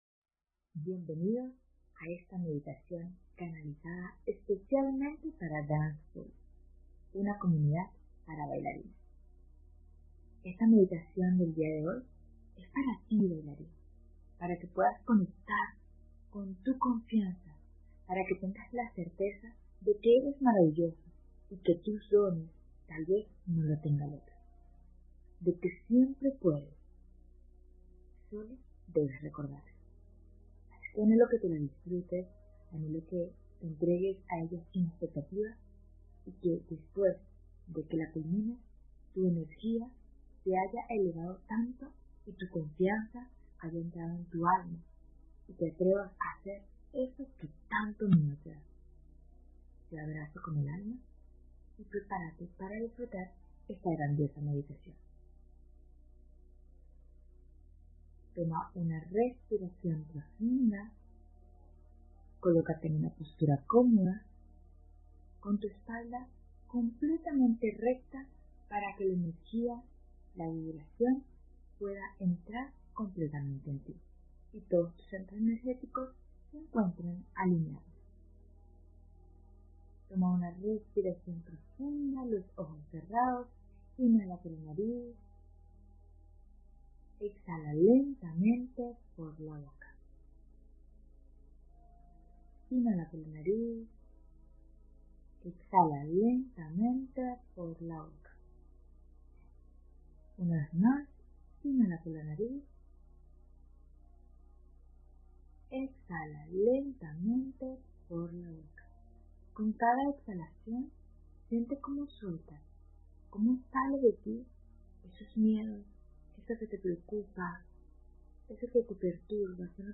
Meditación Guiada: La danza de la mente